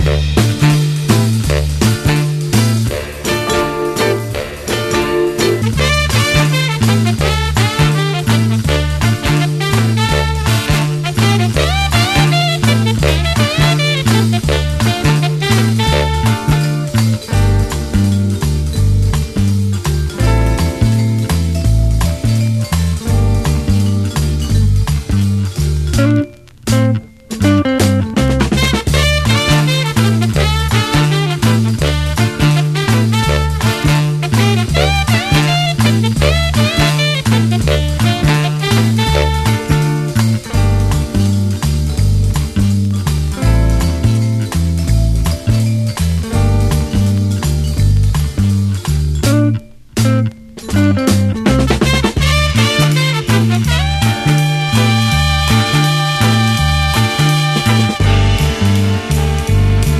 JAPANESE FOLK
NEW ROCK (JPN)